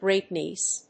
アクセントgréat‐nìece